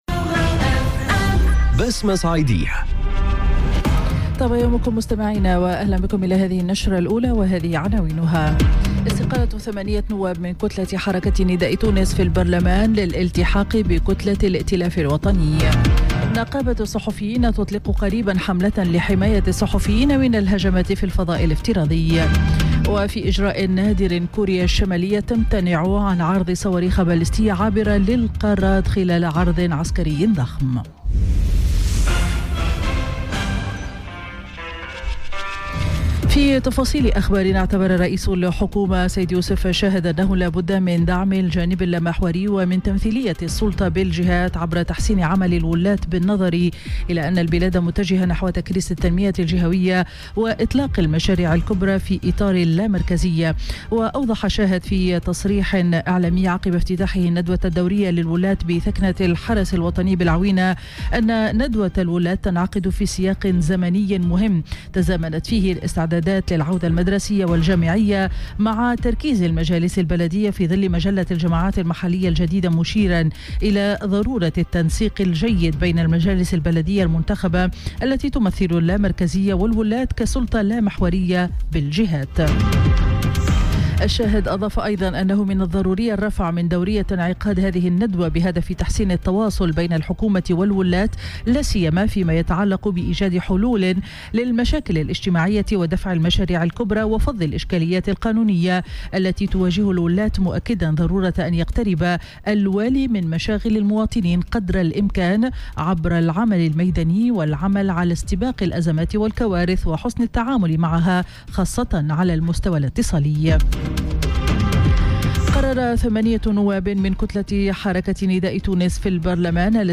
نشرة أخبار السابعة صباحا ليوم الأحد 9 سبتمبر 2018